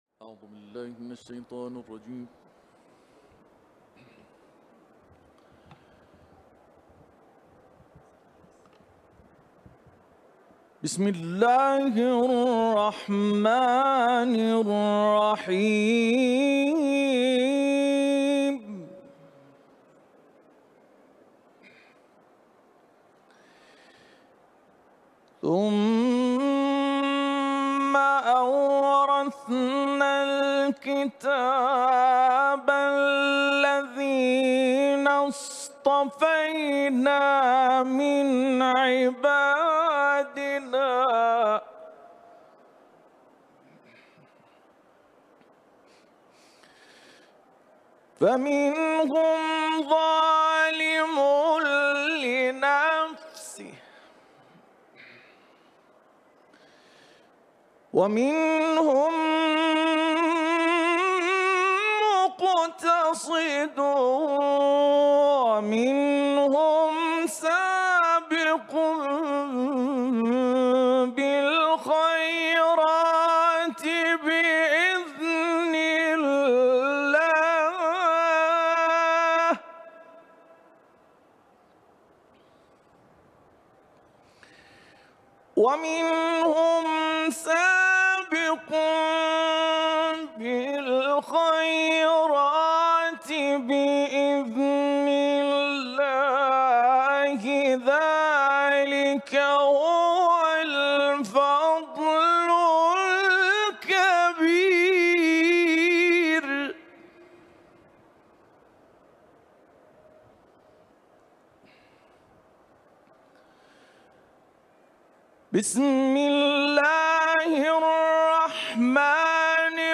تلاوت قرآن